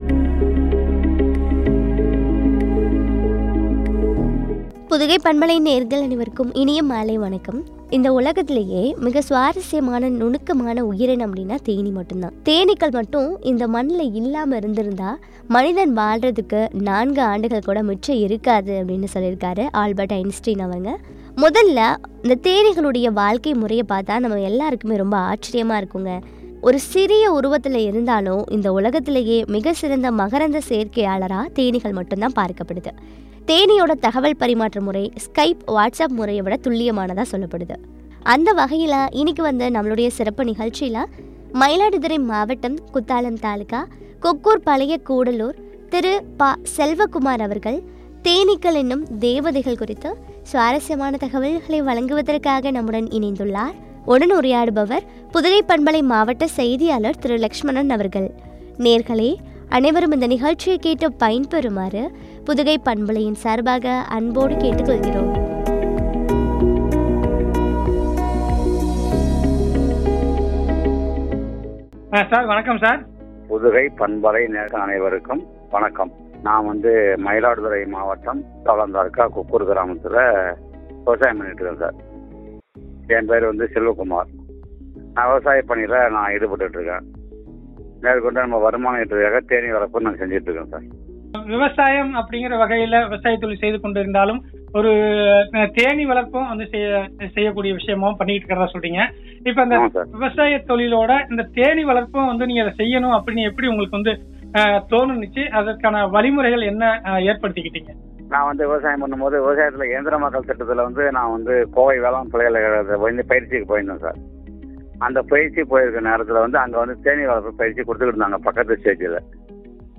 “தேனீக்கள் எனும் தேவதைகள்” குறித்து வழங்கிய உரையாடல்.